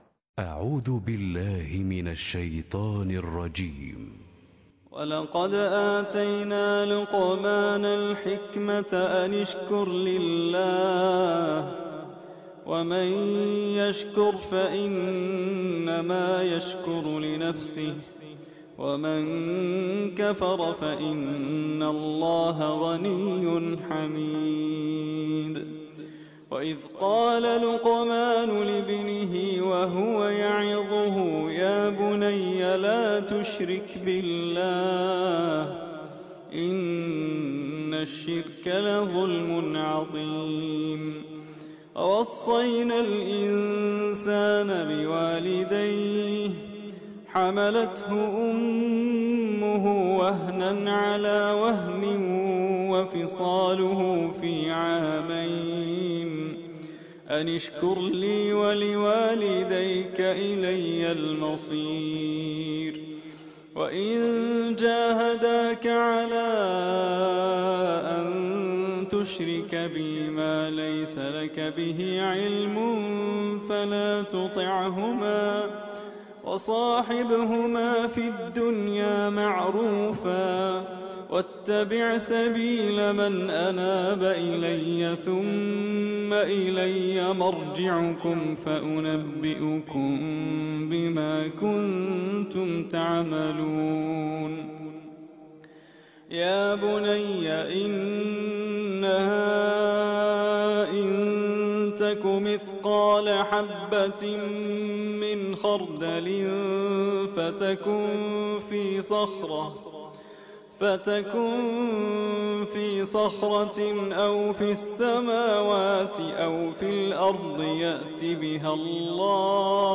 تلاوة ندية من سورة لقمان